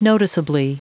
Prononciation du mot noticeably en anglais (fichier audio)